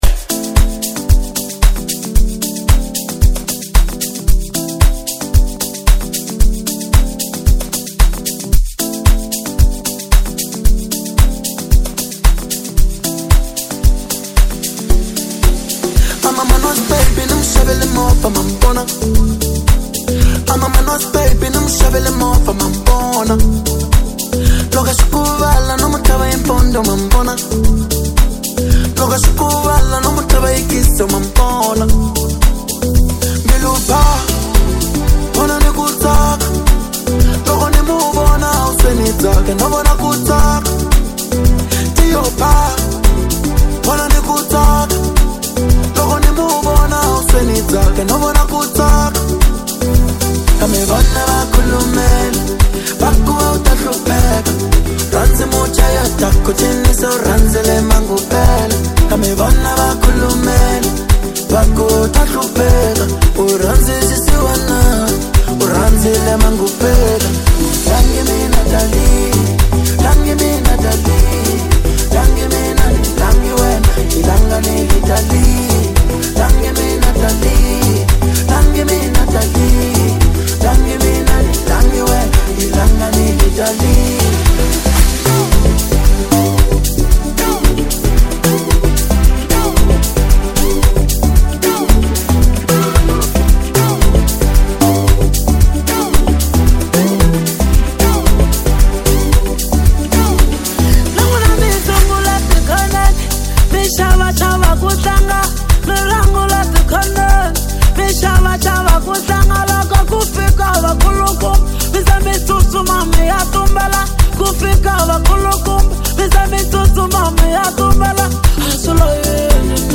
Genre Bolo House